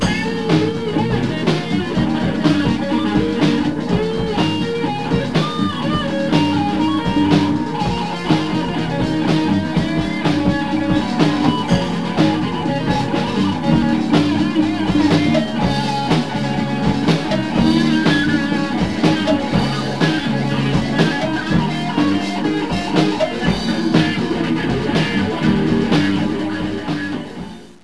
Le guitariste électrique - Solos composés
Exemple de ce style de solo